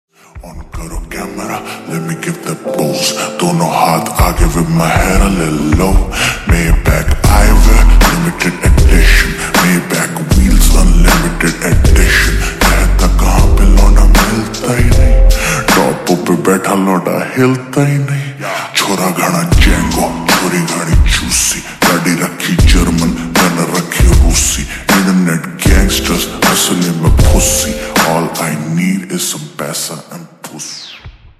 Punjabi sang